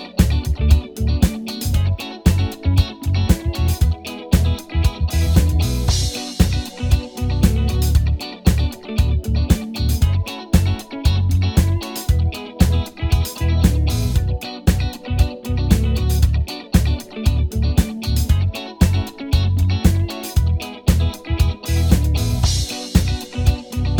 Minus Piano Pop (2010s) 4:17 Buy £1.50